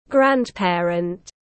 Ông bà tiếng anh gọi là grandparent, phiên âm tiếng anh đọc là /ˈɡræn.peə.rənt/.
Grandparent /ˈɡræn.peə.rənt/